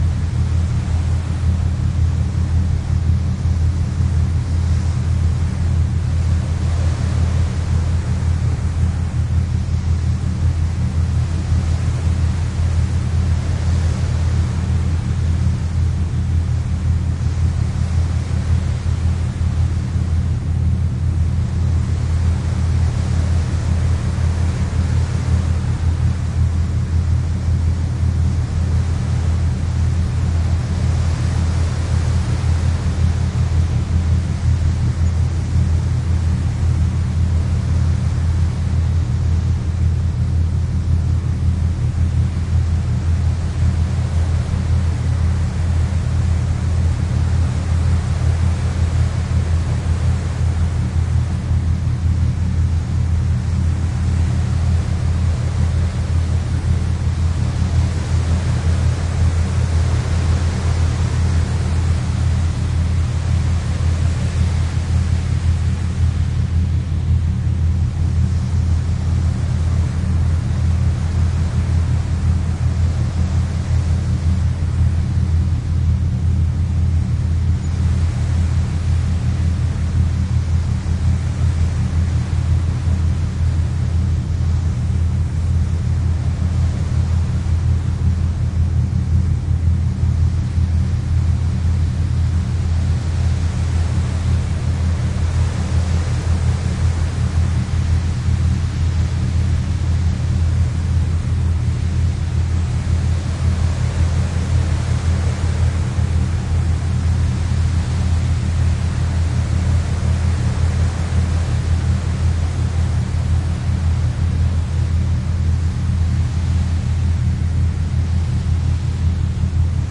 描述：大渡轮"Color Fantasy"的船头波浪，从船的迎风面的第七层甲板上录制，当时船在从奥斯陆到基尔的路上穿过卡特加特海峡。用Telinga Pro 8 Stereo Dat（不带碟）在Zoom H2n.
标签： 渡船 波浪 海洋 弓波
声道立体声